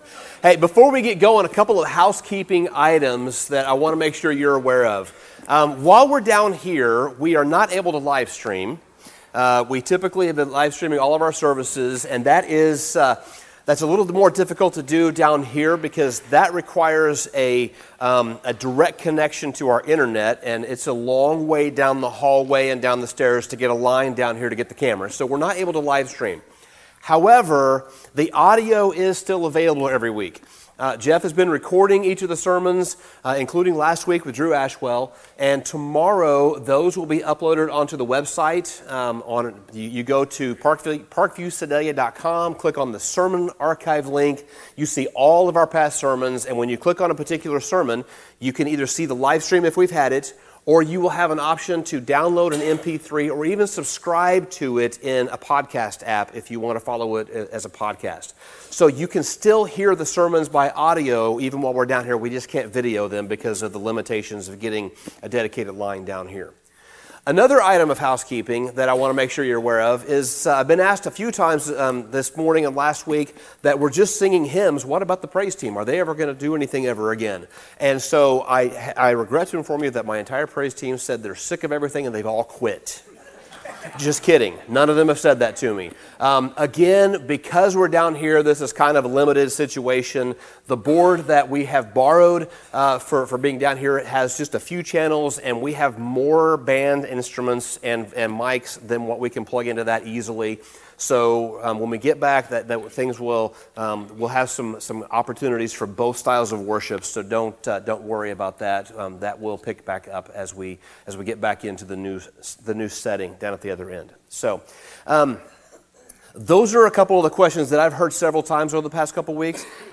Sermon Summary A healthy church is a church that shows compassion for the needs of others.